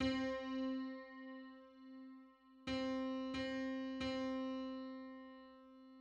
Just: 160/159 = 10.85 cents. Limit: 5-limit.
Public domain Public domain false false This media depicts a musical interval outside of a specific musical context.